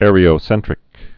(ărē-ō-sĕntrĭk)